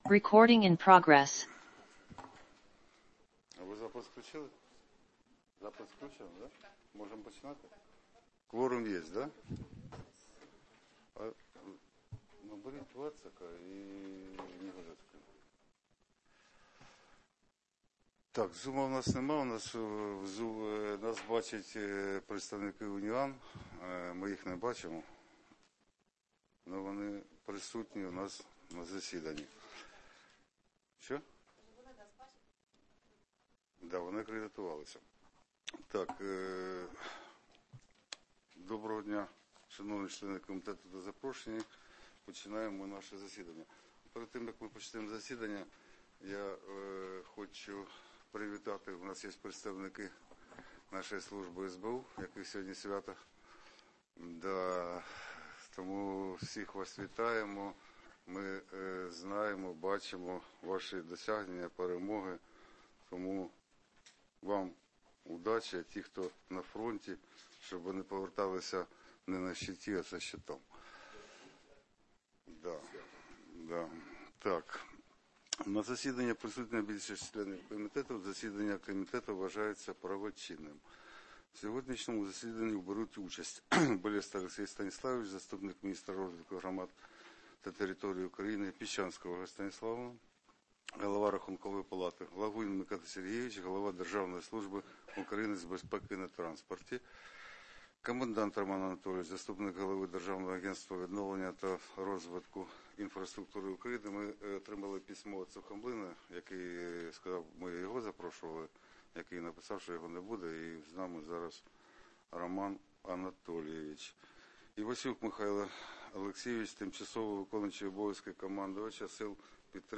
Засідання Комітету 25.03.2026 р.